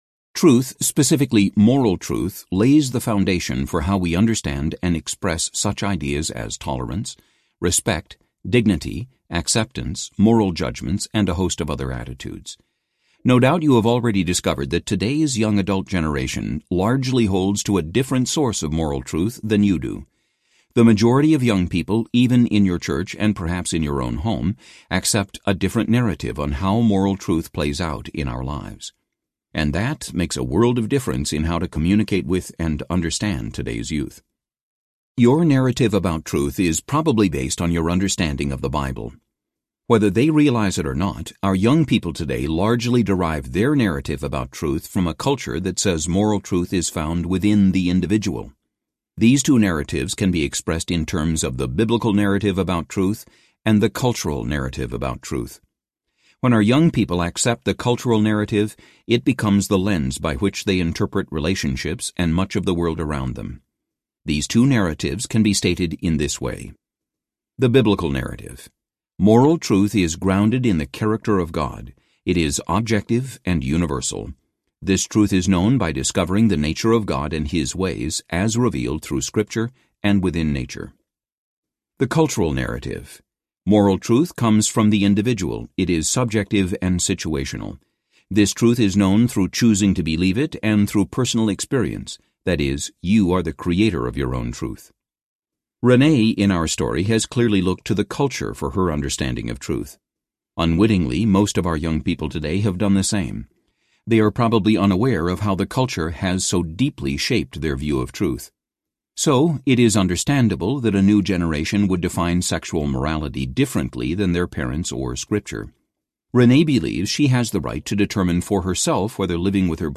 The Beauty of Intolerance Audiobook
Narrator
5.7 Hrs. – Unabridged